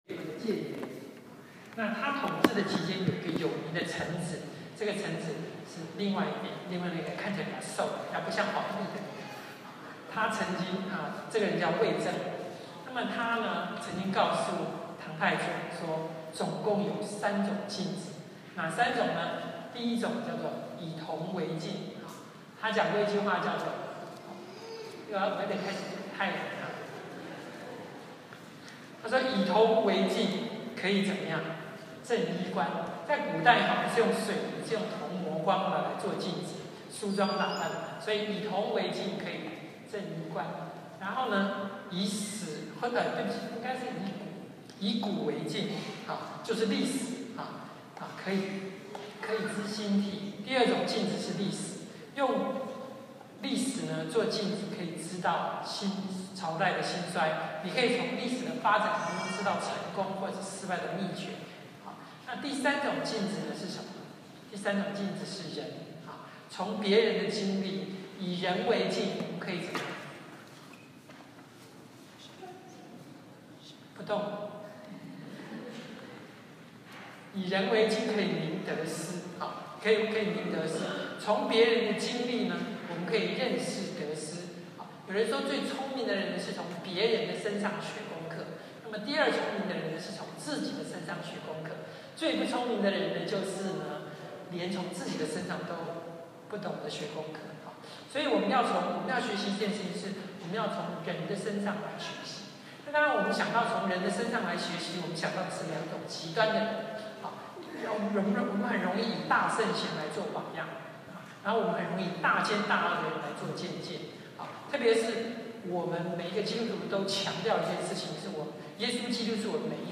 Sermon | CBCGB
Sermon Audio